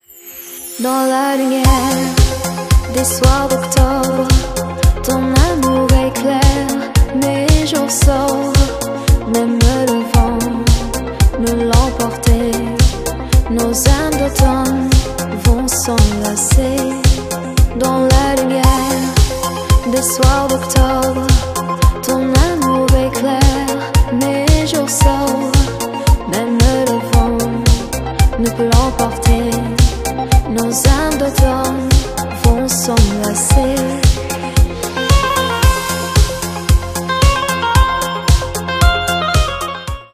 женский вокал , deep house